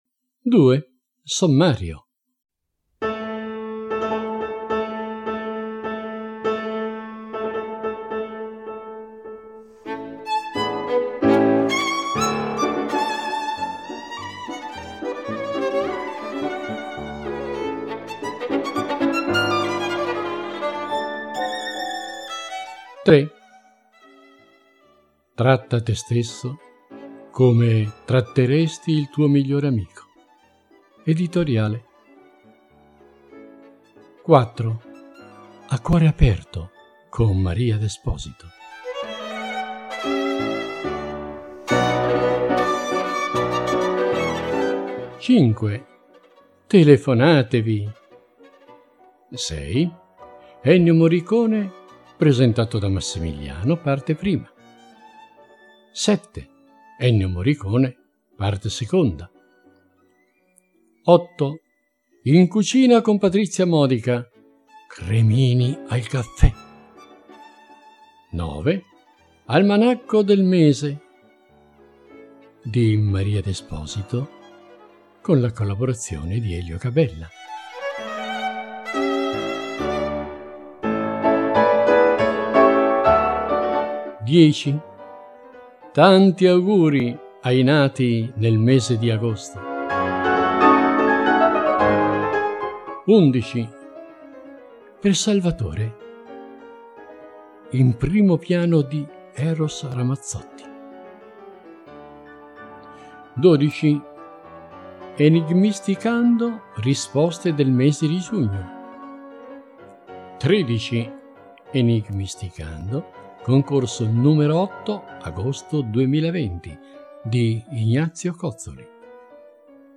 Barzellette, proverbi d’Italia, auguri per i compleanni del mese, comicità e musica per tutti i gusti, completano l’offerta.